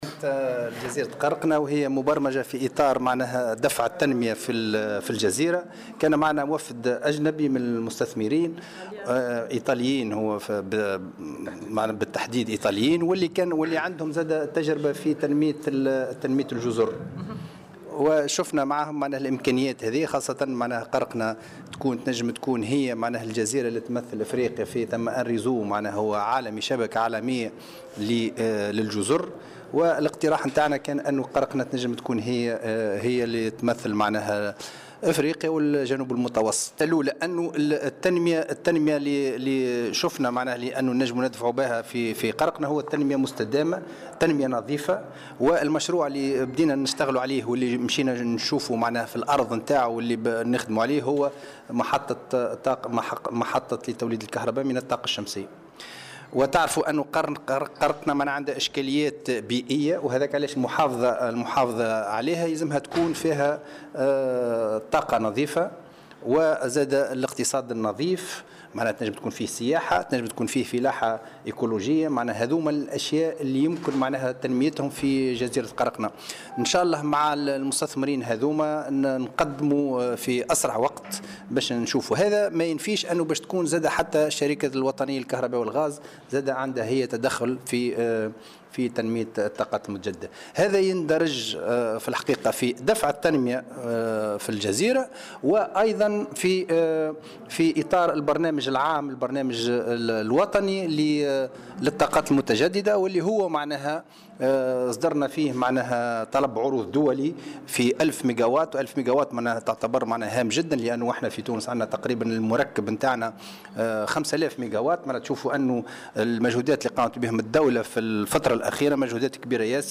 وأضاف الوزير في تصريح لمراسل "الجوهرة أف أم" أن هذه الزيارة تندرج في إطار دفع التنمية في الجزيرة وخاصة في مجال التنمية المستدامة و الطاقات المتجدّدة، مشيرا إلى أنه يتم العمل حاليا على إنجاز مشروع محطة لتوليد الكهرباء من الطاقة الشمسية في الجهة وذلك في إطار البرنامج الوطني للطاقات المتجددة.